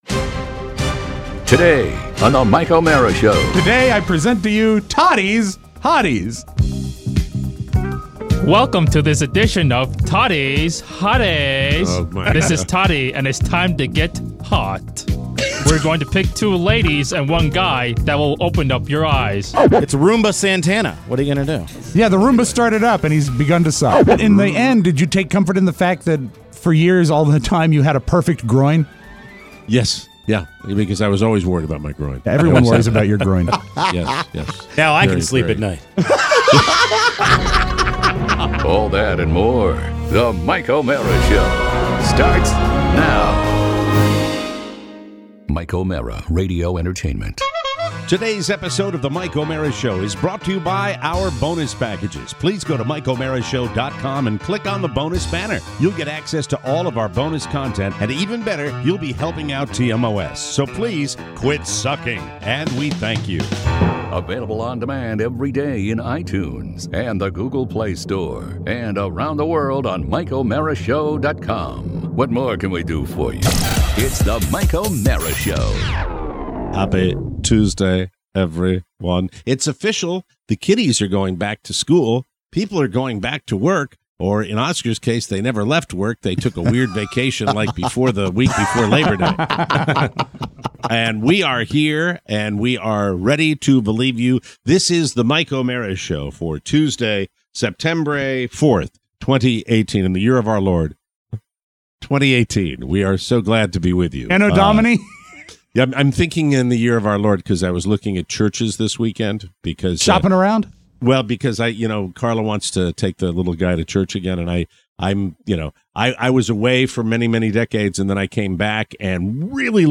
Back in the studio